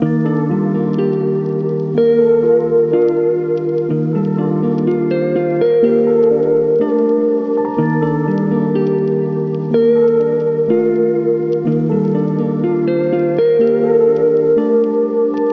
Soft Relaxing Chill